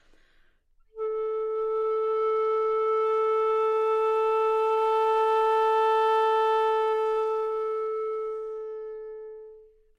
长笛单音 " 长笛 A4
描述：在巴塞罗那Universitat Pompeu Fabra音乐技术集团的goodsounds.org项目的背景下录制。
Tag: 好声音 单注 多样本 A4 纽曼-U87 长笛